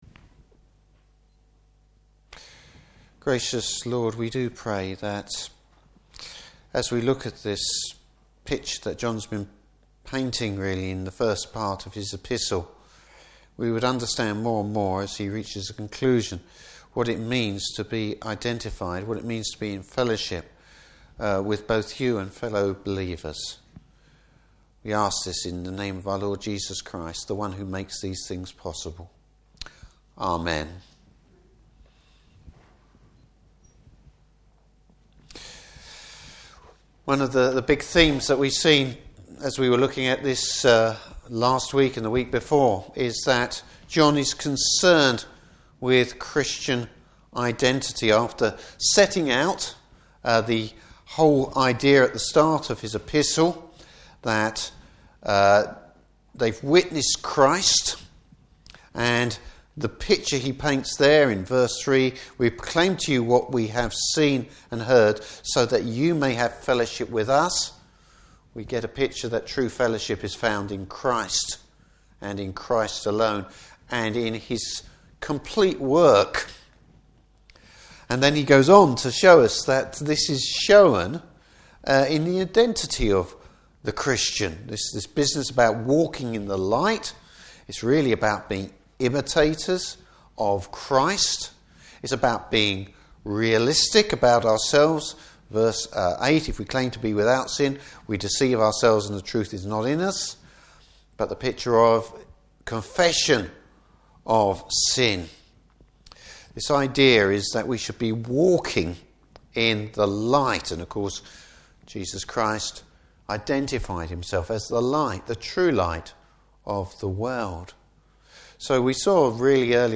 Service Type: Evening Service Bible Text: 1 John 2:1-14.